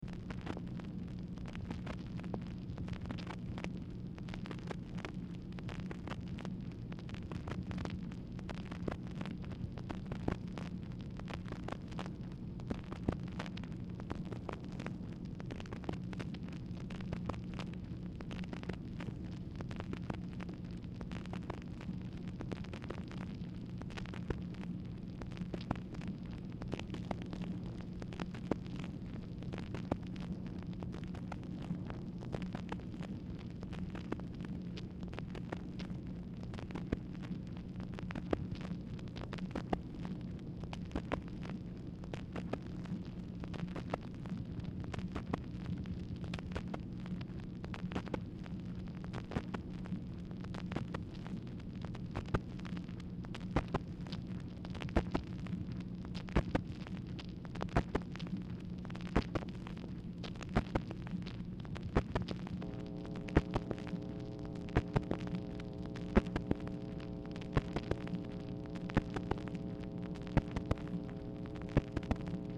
Telephone conversation # 4738, sound recording, MACHINE NOISE, 8/5/1964, time unknown | Discover LBJ
Format Dictation belt
Specific Item Type Telephone conversation